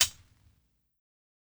Closed Hats
HIHAT_FPROG.wav